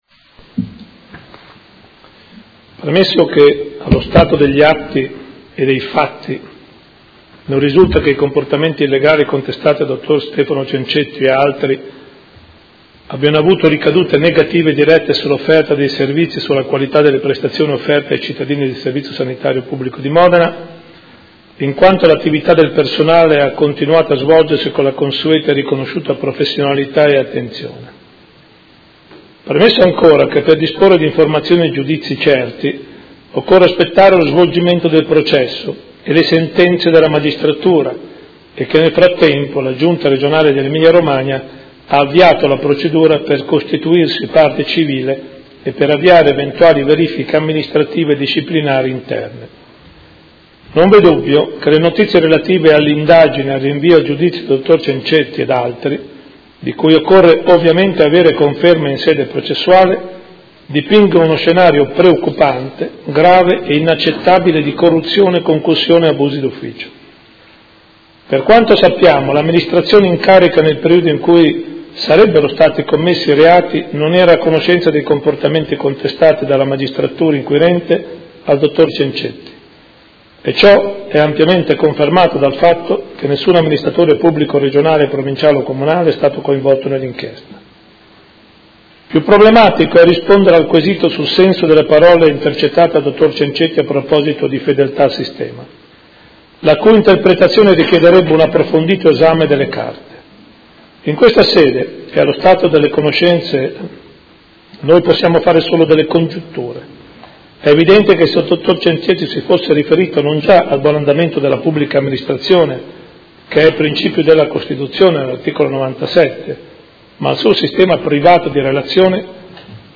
Seduta del 22/09/2016 Interrogazione dei Consiglieri Galli e Morandi (F.I.) avente per oggetto: Dalle intercettazioni di Stefano Cencetti, già Direttore Generale del Policlinico, pubblicate sulla stampa, si evince un perverso intreccio politico/sanitario nella Sanità modenese con affermazioni gravi, ma che non ci stupiscono, dove si afferma: “io sono fedele al Sistema: c’è roba grossa non deve saltare”. Risponde il Sindaco